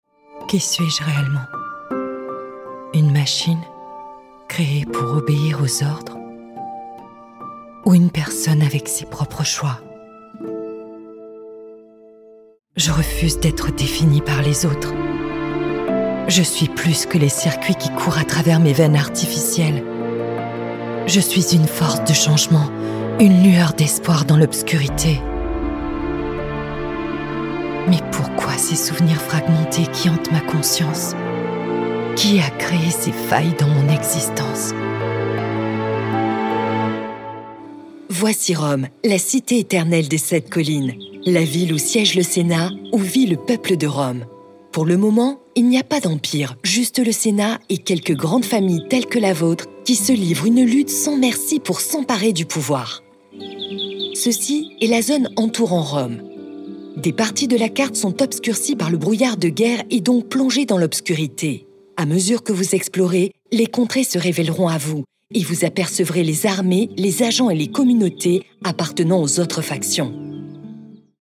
French Female Voice Over Artist
Female
Assured, Authoritative, Bright, Bubbly, Character, Children, Confident, Cool, Corporate, Deep, Engaging, Friendly, Natural, Posh, Reassuring, Soft, Versatile
French standard
Microphone: NEUMANN TLM49
Audio equipment: Apollo Twin MKII universal audio - Cleransonic recording booth